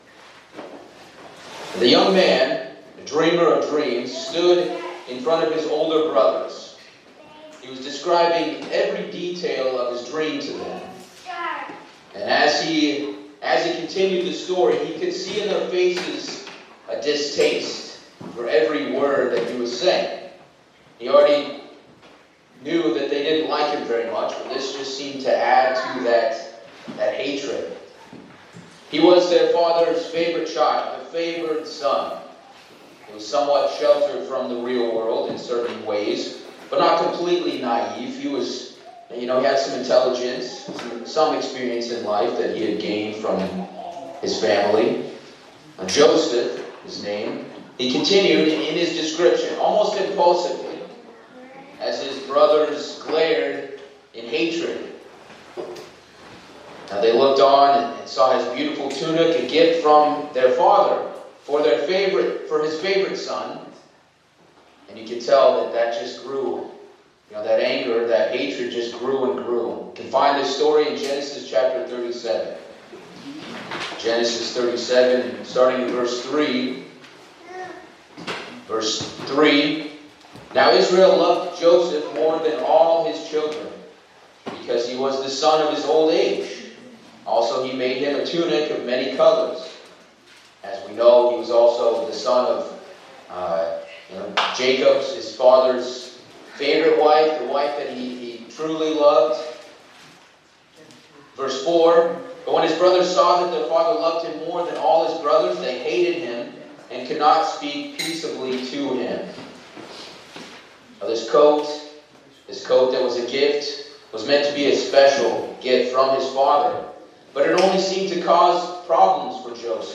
This sermon discusses the biblical story of Joseph from the Book of Genesis, focusing on the tests he faced throughout his life, his character development, and the overarching theme of God's providence. The sermon emphasizes the importance of loyalty, faith, kindness, patience, wisdom, and humility as Joseph navigates through various trials and tribulations.